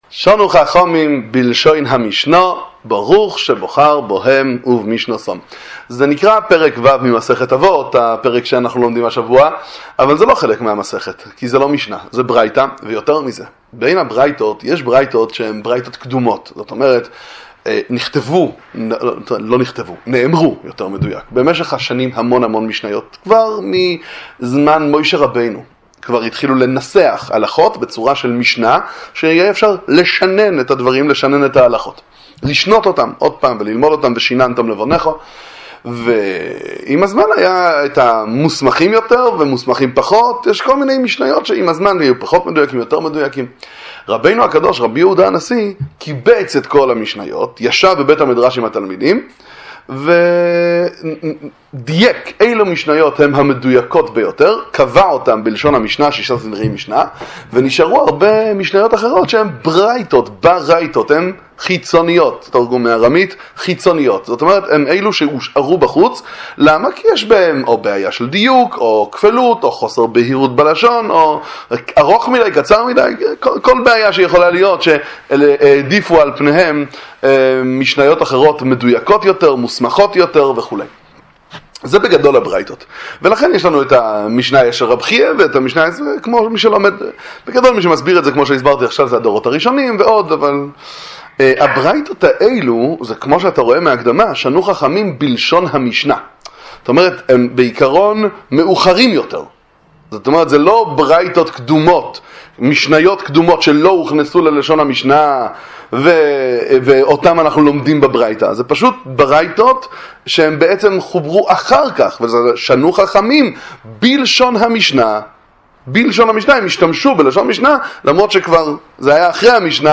דוגמא אישית – דבר תורה קצר לפרקי אבות פרק ו' פתיח למשנה א' – ברוך שבחר בהם ובמשנתם